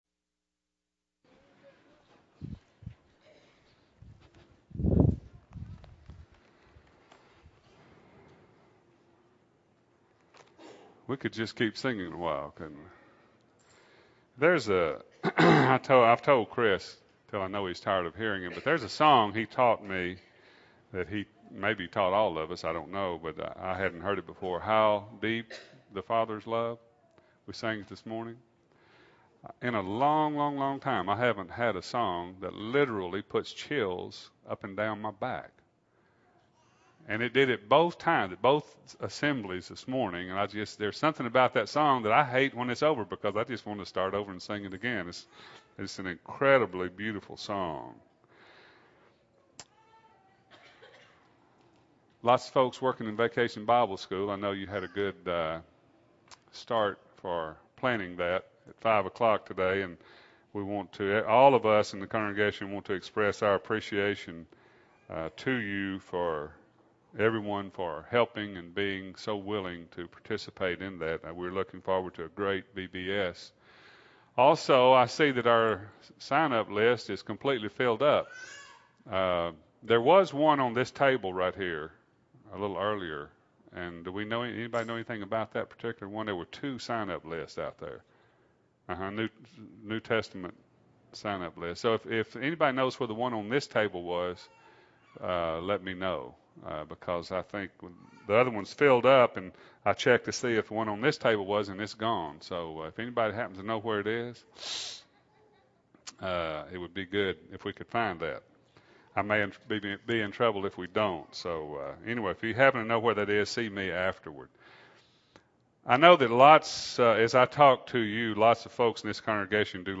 2008-05-04 – Sunday PM Sermon – Bible Lesson Recording